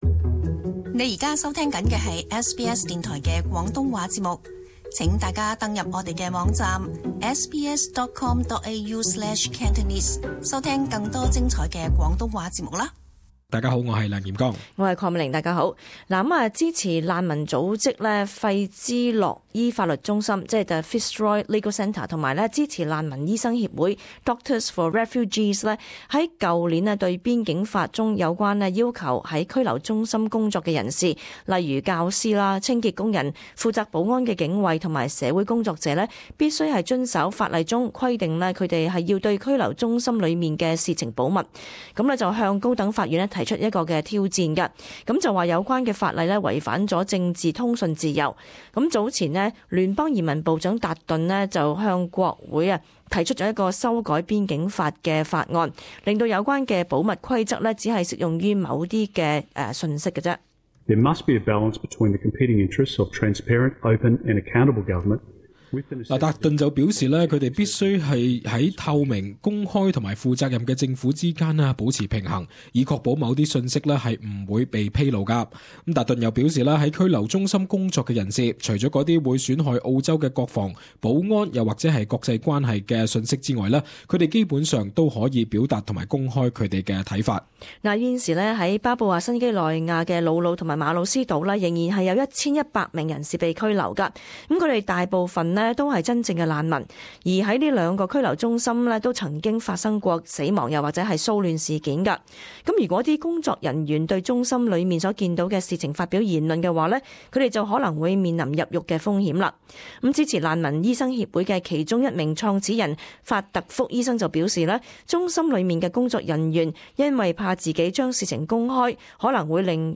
【時事報導】澳洲會出現暴力革命？